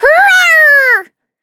Taily-Vox_Attack5.wav